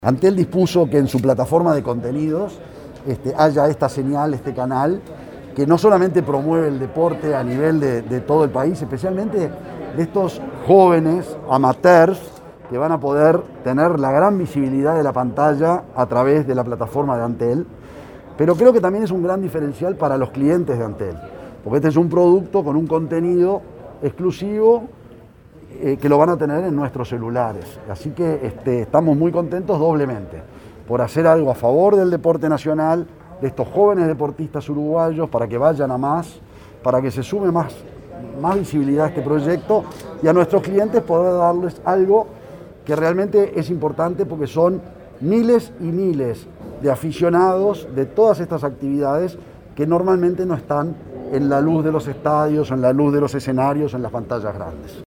Declaraciones del presidente de Antel, Gabriel Gurméndez, a la prensa
El presidente de Antel, Gabriel Gurméndez, participó este viernes 10 en la Torre de las Telecomunicaciones en el lanzamiento de la primera señal de